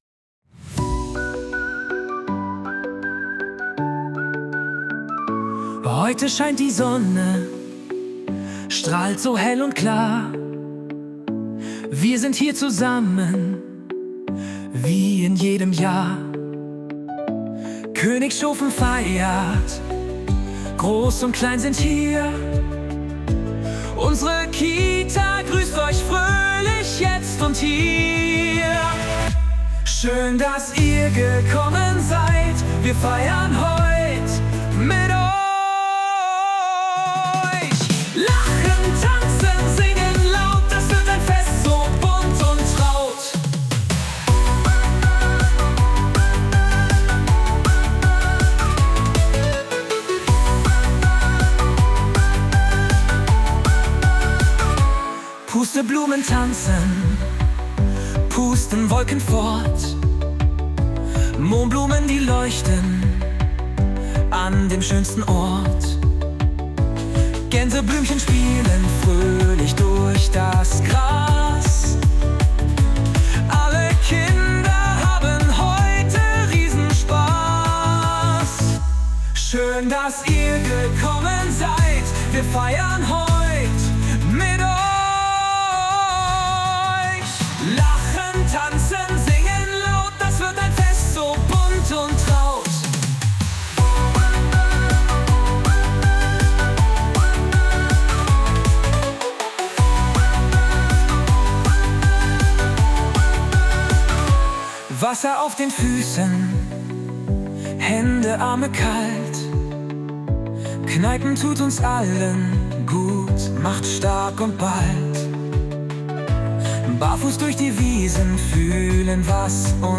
Die KI liefert zu eigenen oder ebenfalls mit KI generierten Texten komplette Lieder mit realistischen Gesangsstimmen, Melodien und Instrumenten, die dann individuell angepasst werden können.